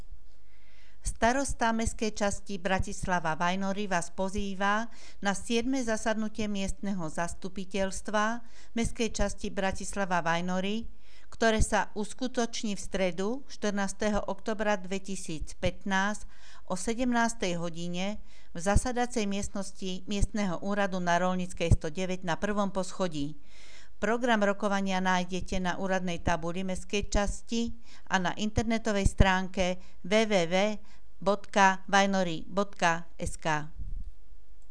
Hlásenie miestneho rozhlasu 13.10.2015